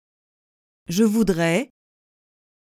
Je voudrais pronunciation
12-je-voudrais.mp3